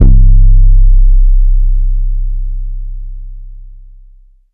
Trap kick drum Free sound effects and audio clips
• phonk kicks oneshot 13 - 808 C.wav
Specially designed for phonk type beats, these nasty, layered 808 one shots are just what you need, can also help designing Hip Hop, Trap, Pop, Future Bass or EDM.
phonk_kicks_oneshot__13_-_808_C_hUR.wav